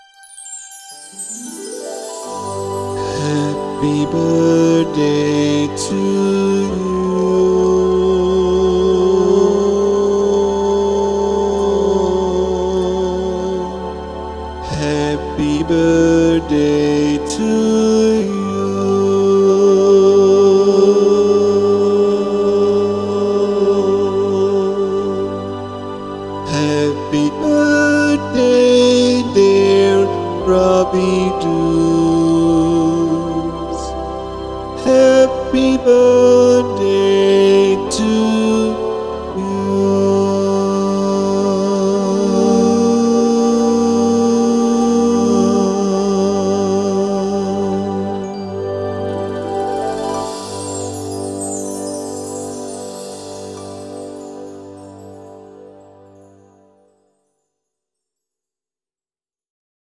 Met het enige verschil dat ik geen MIDIfile gebruik, maar een interne style samen met mijn rechterhand om de exacte melodie te spelen dat de Vocalist moet zingen.
Ik heb hierin geen geluiden voor de hoofdmelodie of andere truuks toegepast, zodat goed de stem te horen is en zo ook het computeractige effect dat ontstaat door de pitch correctie. En geloof het of niet...., maar ik moest echt even oefenen om zo vals te zingen, hahaha .
• Wijzig de modus naar VOCODER en selecteer een aardig galmeffect (80sPOP REV) onder Vocal Effect.